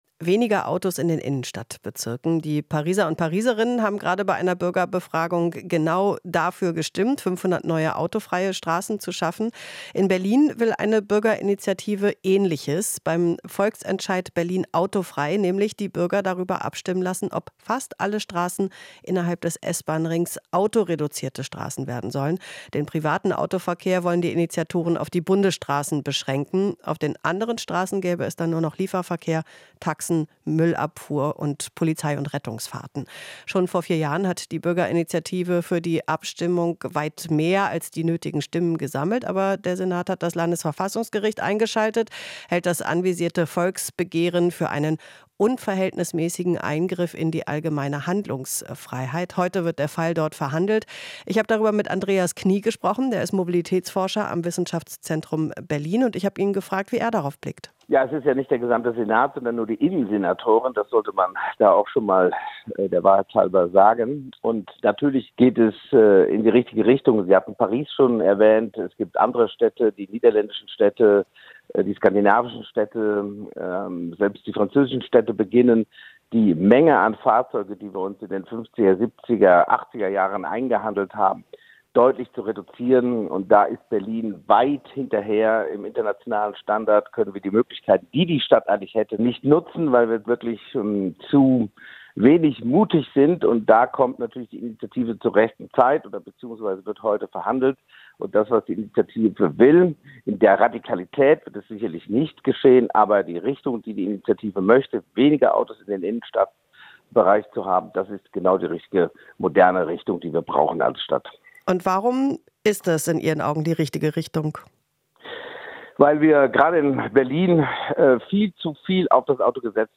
Interview - Mobilitätsforscher: Volksbegehren "Berlin autofrei" geht in richtige Richtung